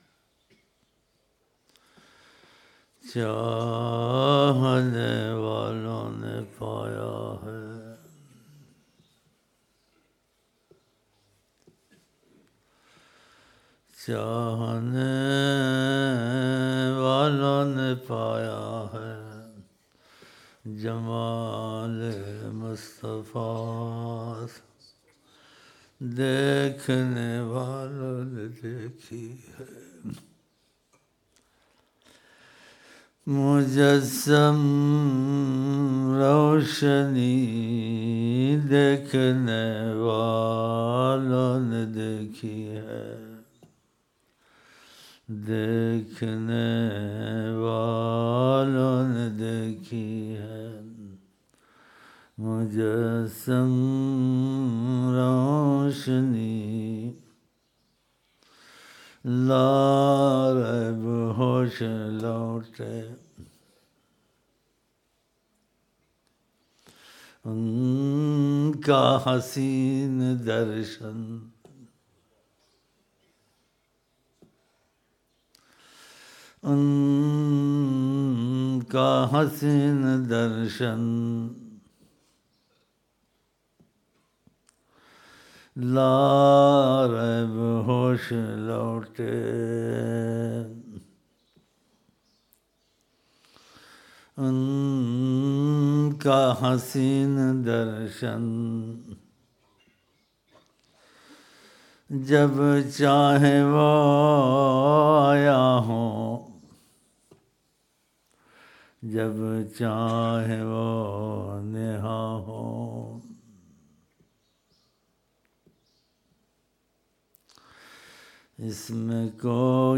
فجر محفل